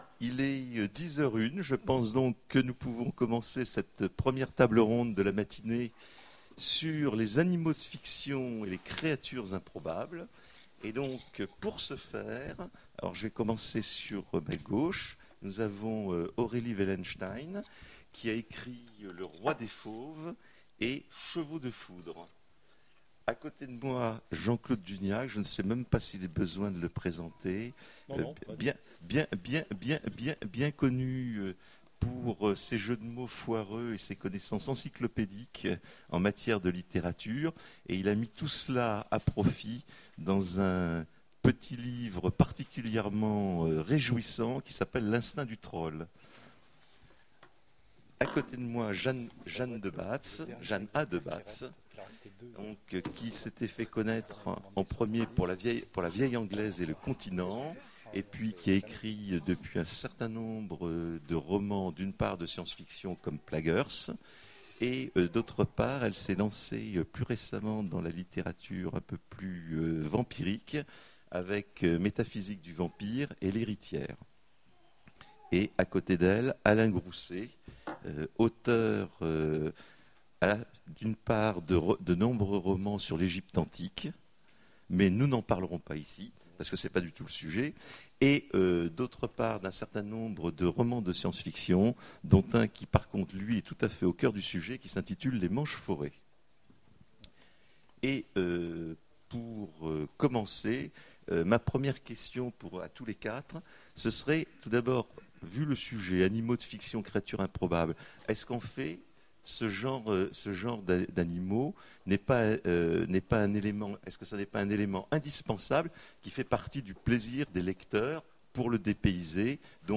Imaginales 2015 : Conférence Animaux de fiction